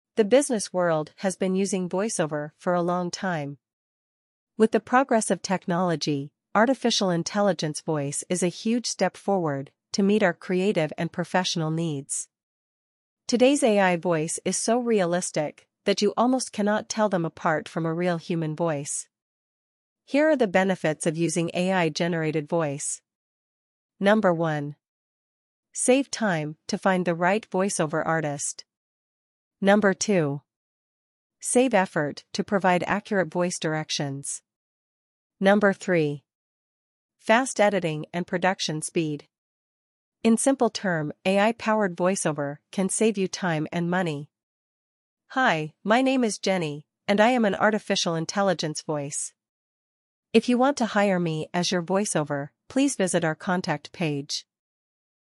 voiceover
Voiceover - Female